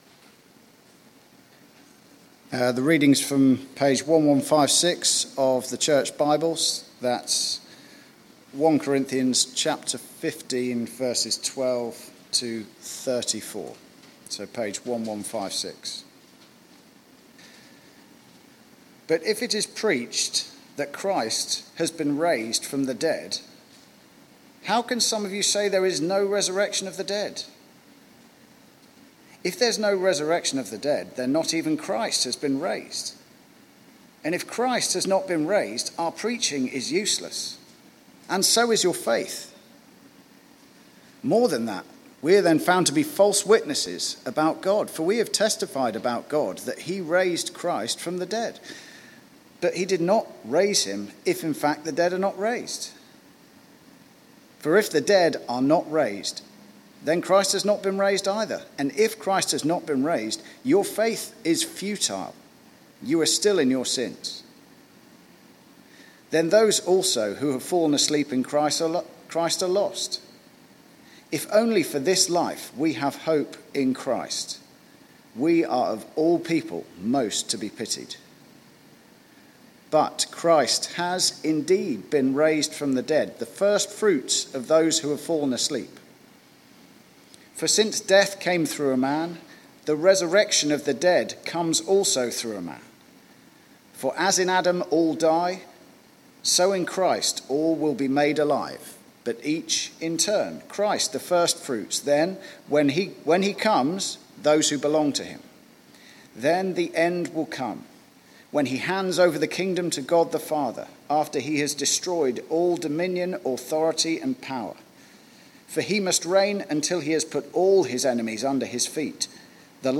Church at the Green Sunday 4pm
Theme: Sermon